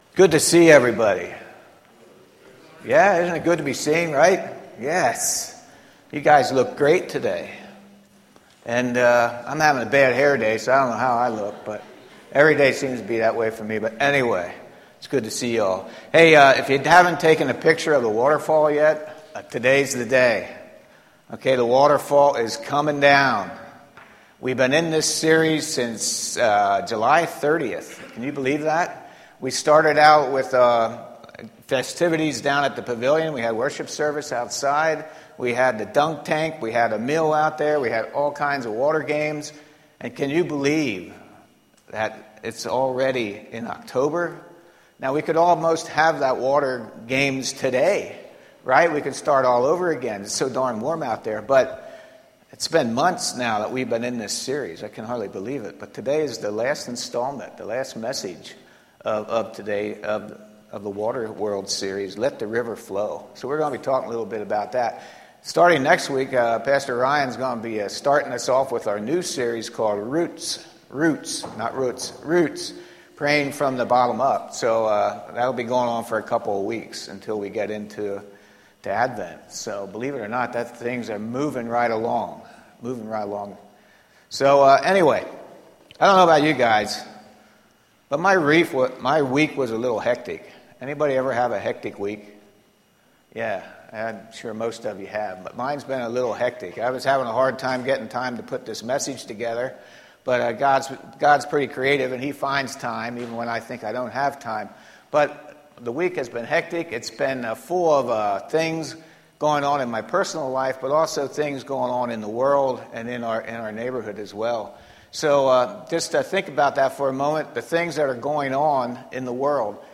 A message from the series "Water World."